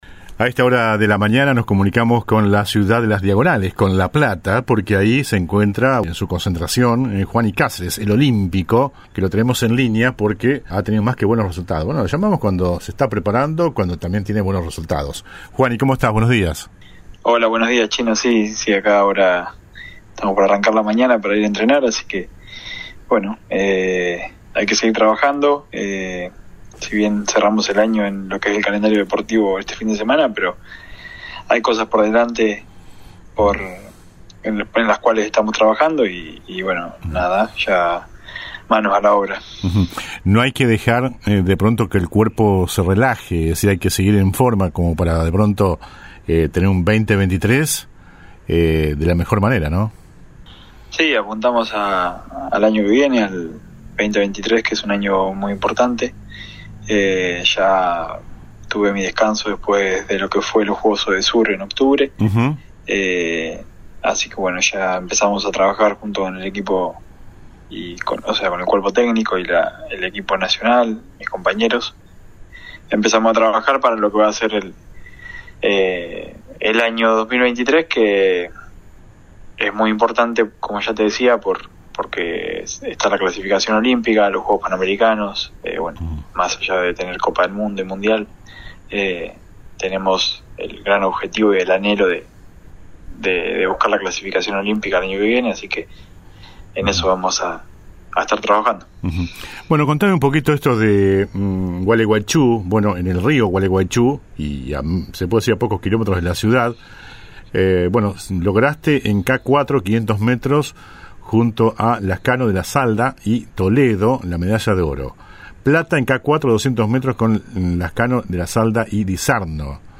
De las notas más salientes del 2022 en el programa «El Periodístico», se destaca la entrevista